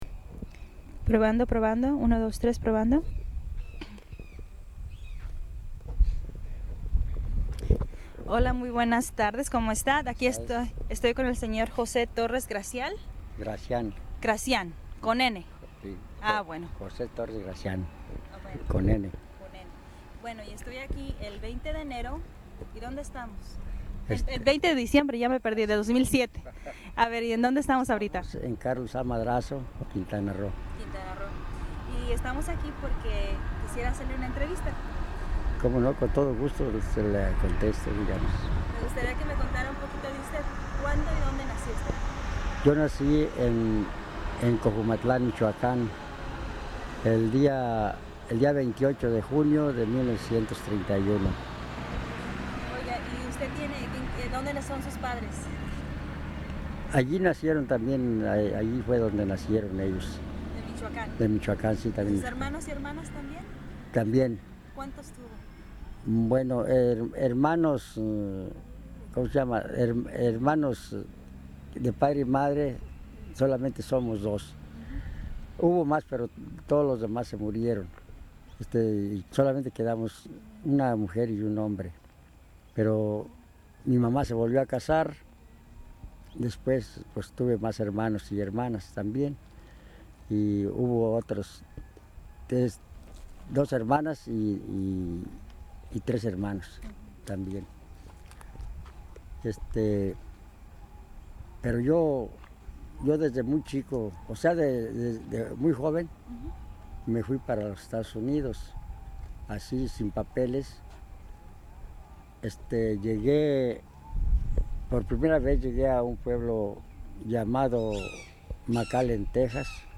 Location Ejido Chachoben, Quintana Roo